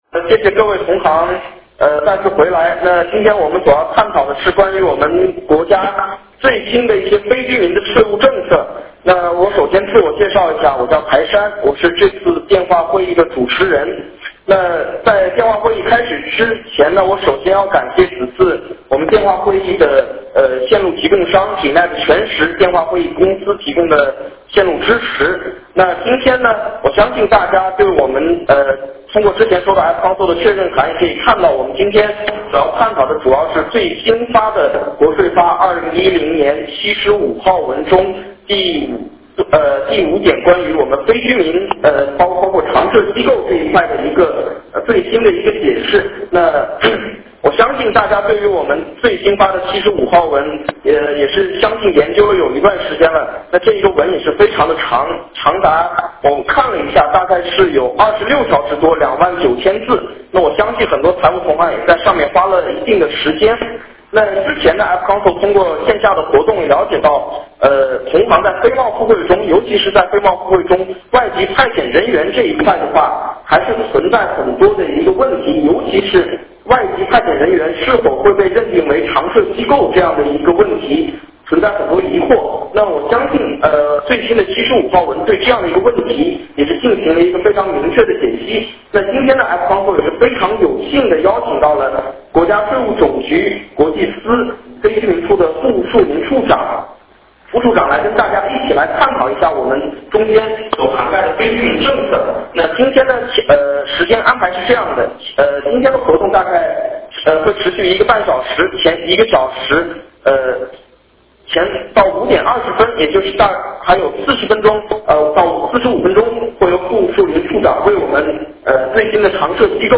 电话会议
国家税务总局国际司 主管官员